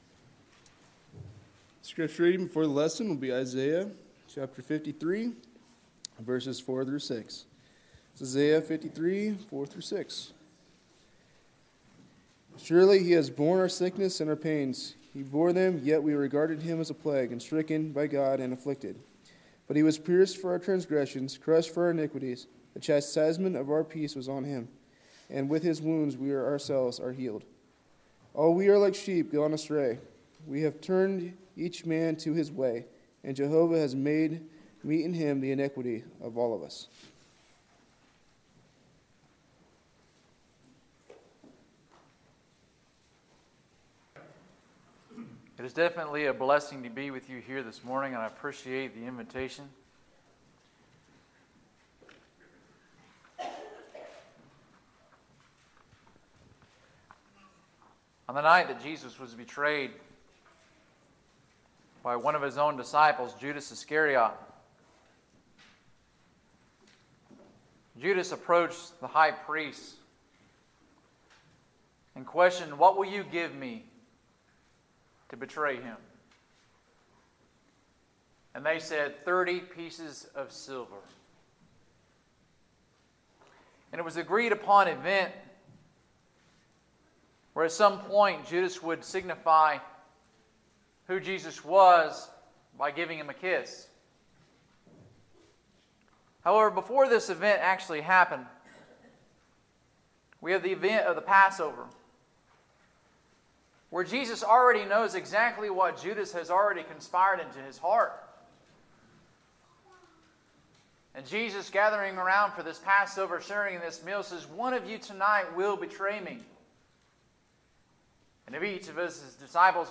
Sermons, April 14, 2019